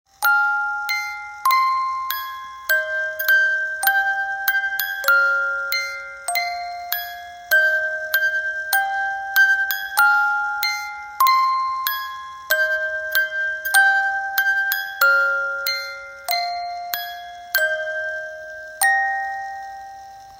Cover , Rock